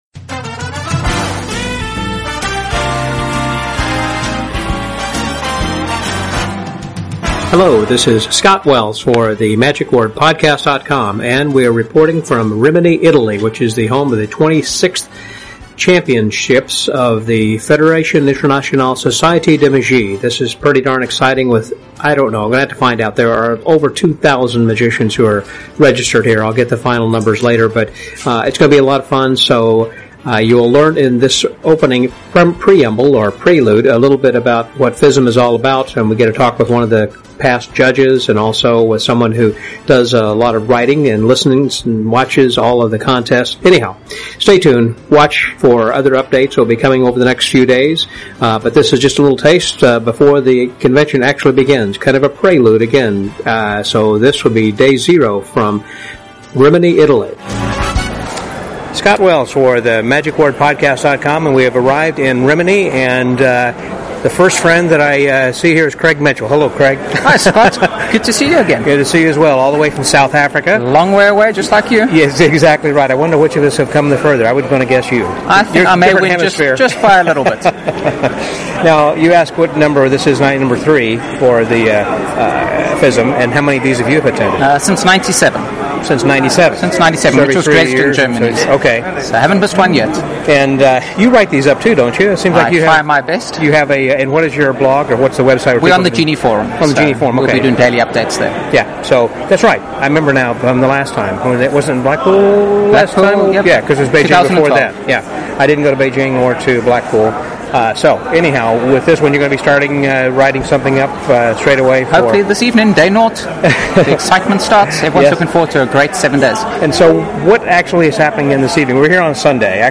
The 26th World Championships of Magic is about to get underway...tomorrow. But first, let's learn a little about FISM and how it's judged and more about the point system and more, plus hear from a few friends I met while waiting to check in at the registration desk.